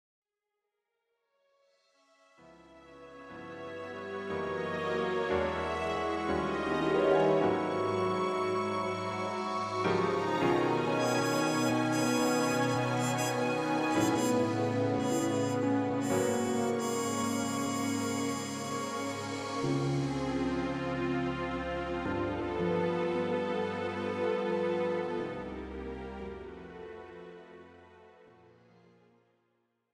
instrumental backing track cover
• Key – D
• Without Backing Vocals
• No Fade